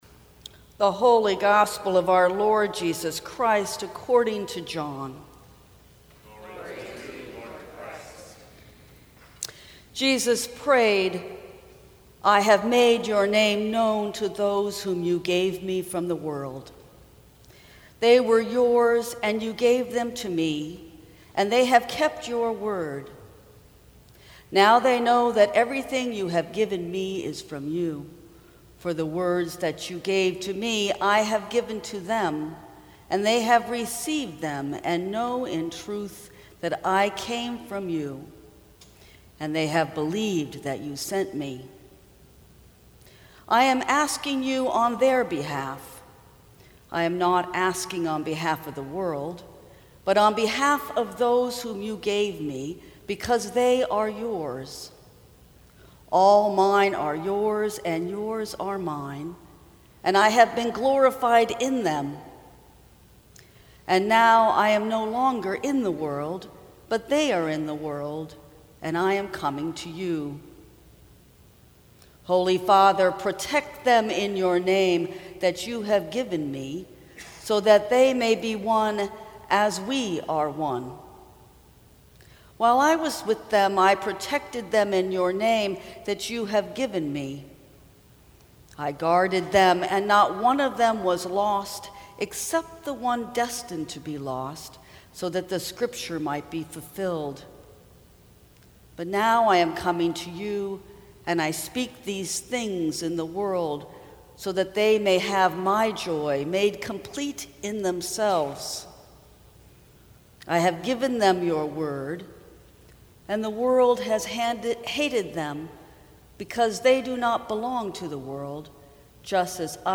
Sermons from St. Cross Episcopal Church Given, Truth, World May 13 2018 | 00:10:13 1x 00:00 / 00:10:13 Subscribe Share Apple Podcasts Spotify Overcast RSS Feed Share Link Embed